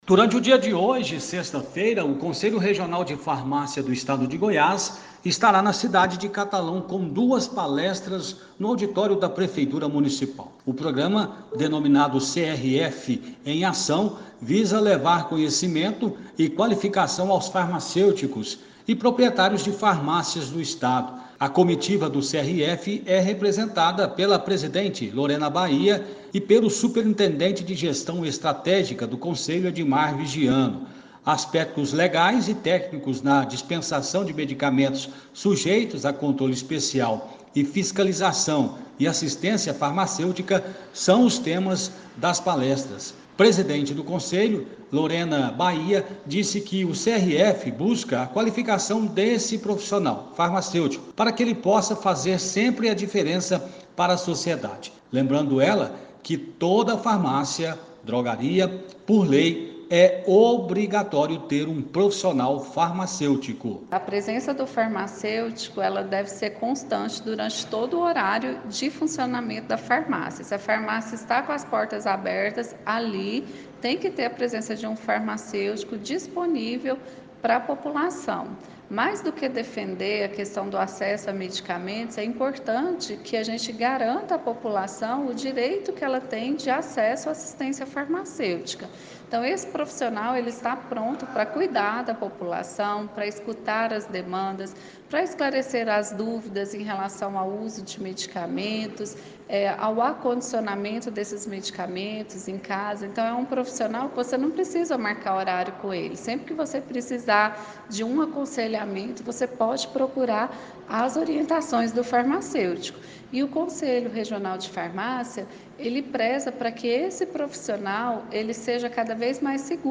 Entrevista à Rádio Cultura